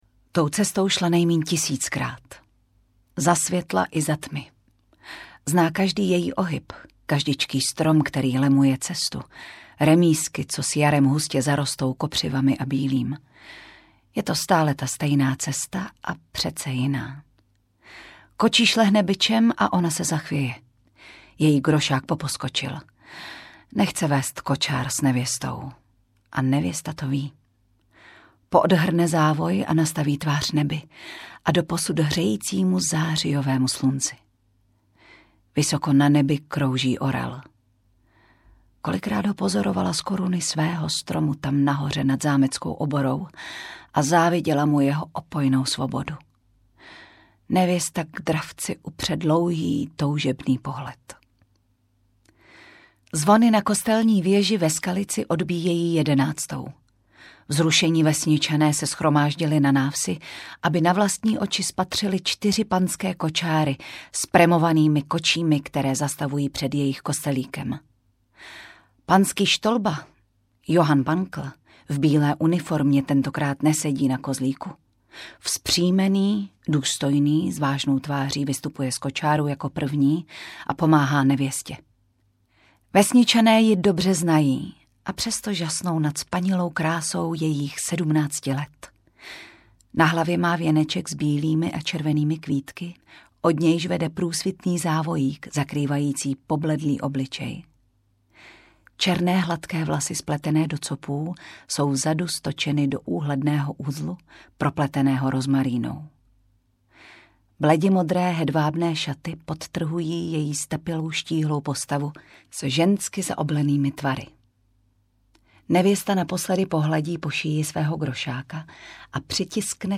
Božena audiokniha
Ukázka z knihy
• InterpretTatiana Dyková, Vojtěch Dyk